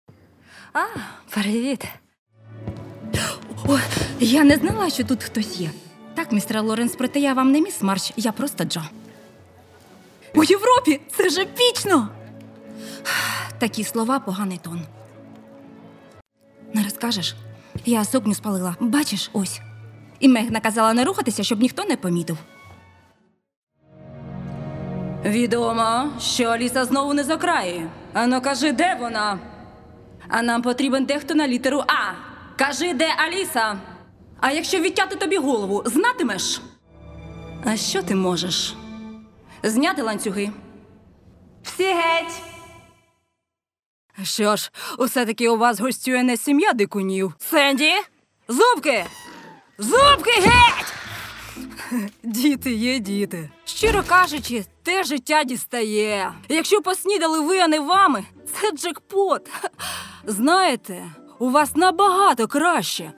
Voice probe.mp3
Жіноча
Маю приємний голос та чарівну посмішку.